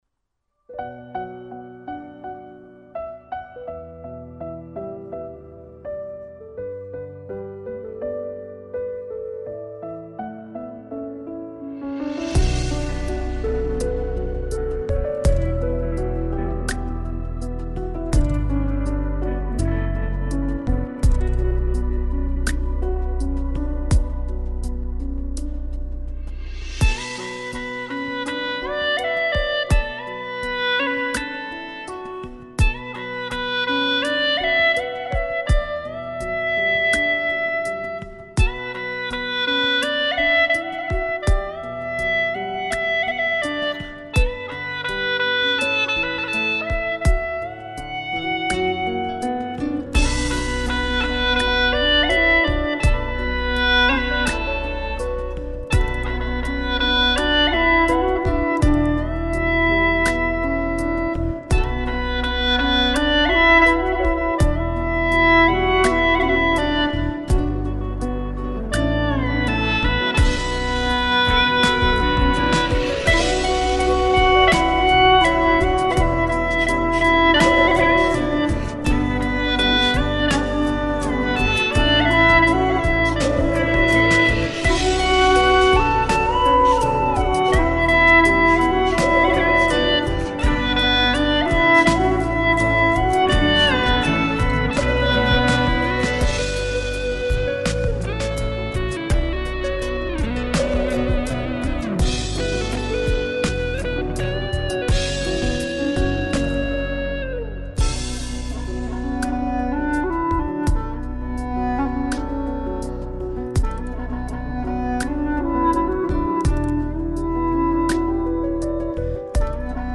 调式 : D 曲类 : 流行
曲调轻柔委婉又略显凄凉
【大小D调】